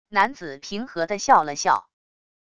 男子平和地笑了笑wav音频